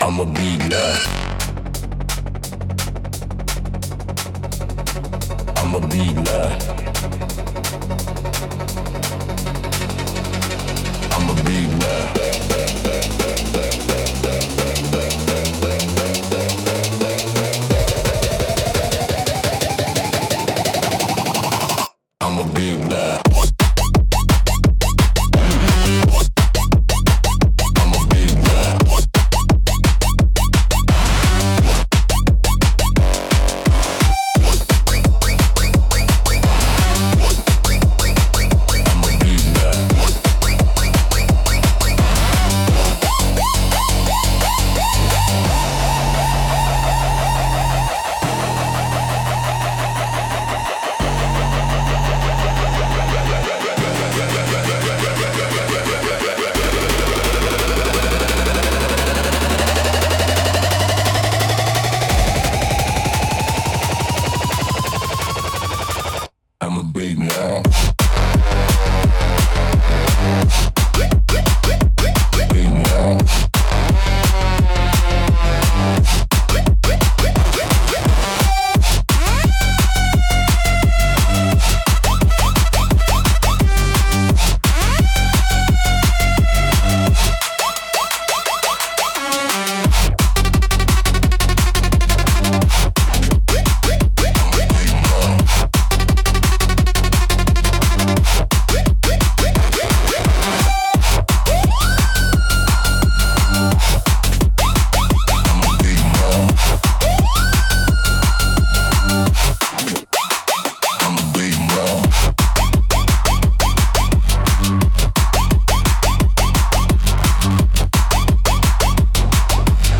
House - Punk